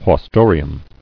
[haus·to·ri·um]